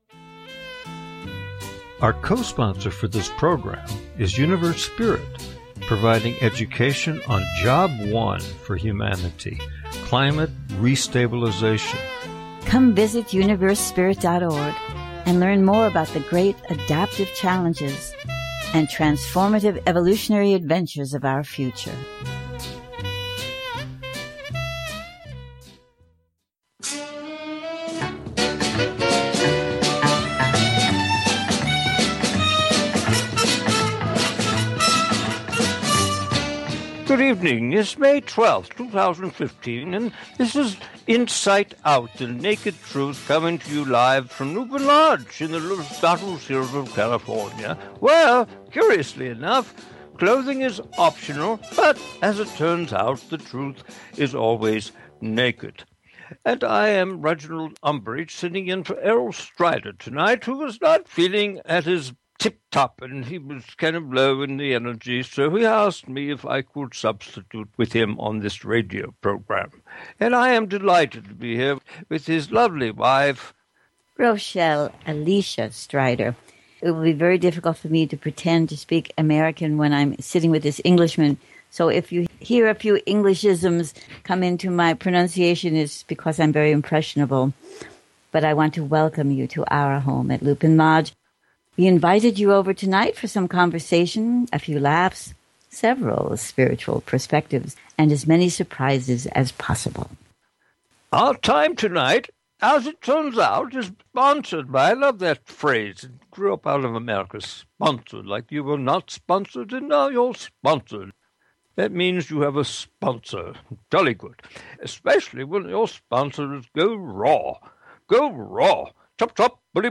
This is a program that we did in May of 2015, but it's been edited it so you can hear the juiciest, fumiest and most insightful parts of that show.